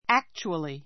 actually 中 A2 ǽktʃuəli ア ク チュアり 副詞 実際に(は) , 実は, （まさかと思うかもしれないが） 本当に It was not a dream; it actually happened.